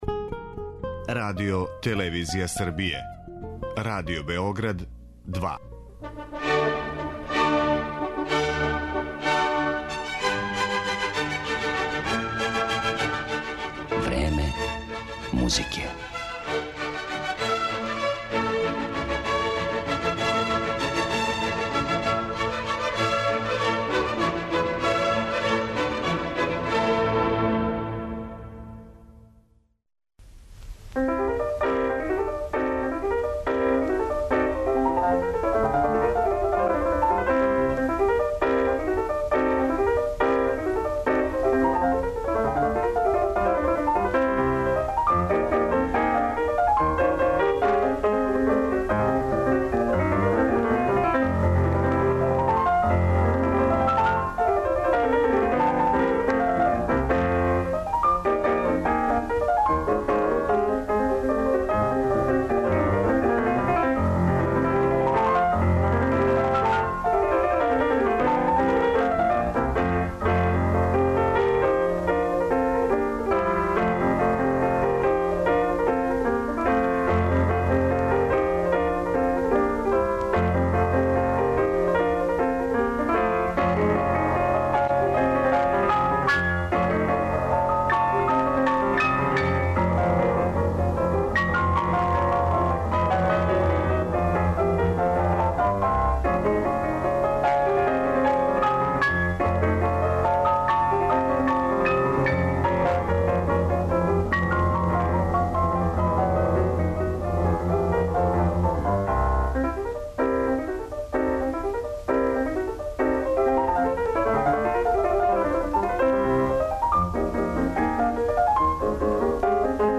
Поводом обележавања 90 година постојања Радио Београда, данас ћете у емисији 'Време музике' слушати избор раритетних архивских снимака који су део богатог фонда радијске фонотеке.
Чућете како су своја дела интерпретирали Сергеј Рахмањинов, Камиј Сен-Санс, Фриц Крајслер, Морис Равел, Игор Стравински, Габријел Форе, Едвард Григ, Александар Скрјабин, Стеван Христић и неколицина других композитора.